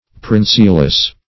Princeless \Prince"less\, a.